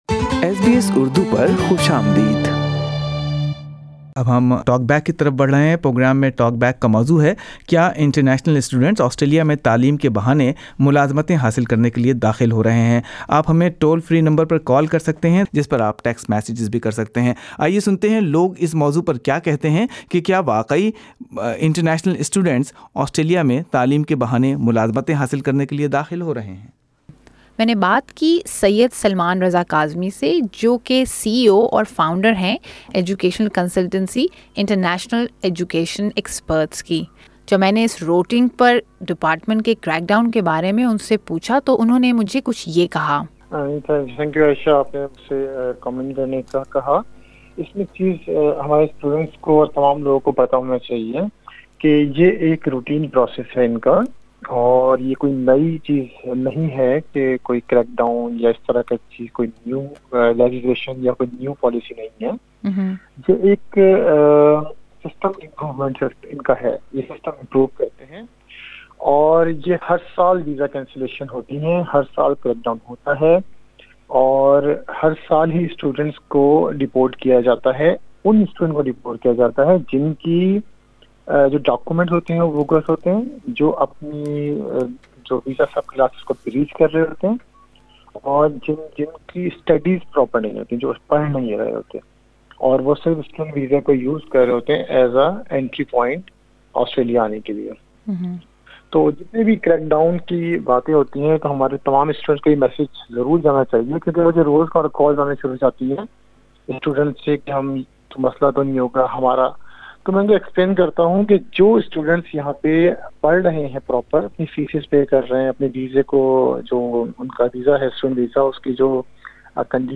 SBS Urdu hosted a talkback session asking the audience and a couple of experts to share their thoughts on the question: Are international students using studies as an excuse to enter Australia and find work?